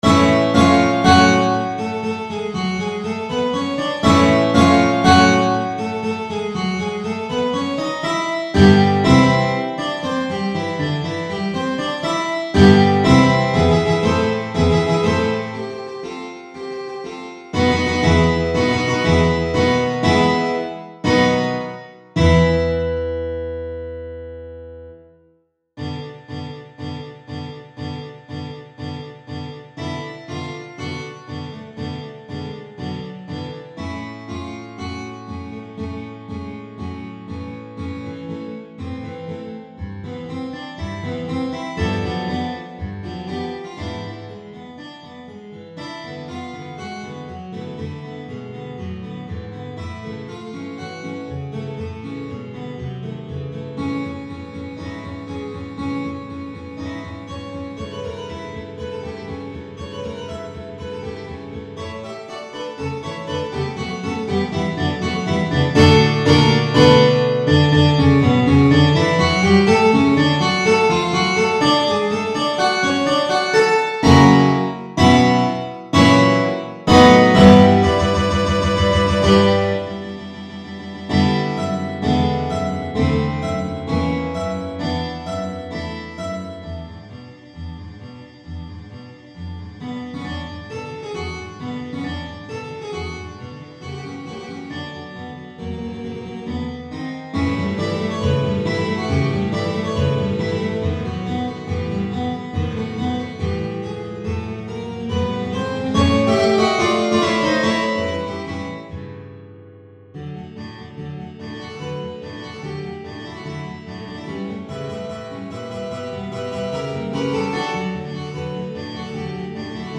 for Guitar Quartet